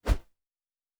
pgs/Assets/Audio/Fantasy Interface Sounds/Whoosh 08.wav at master
Whoosh 08.wav